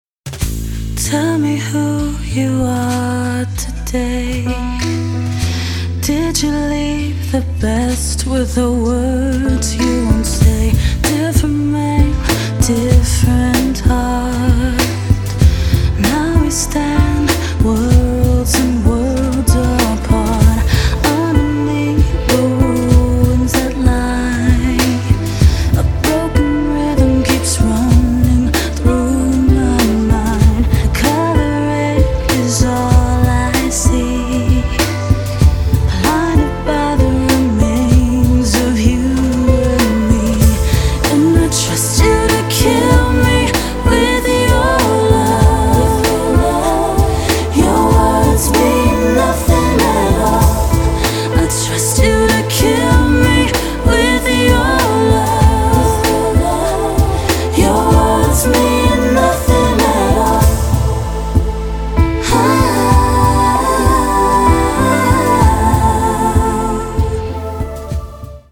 красивые
женский вокал
спокойные
пианино
New Age